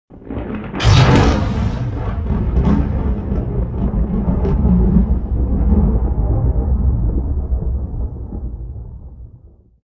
nebula_discharge_thunder01.wav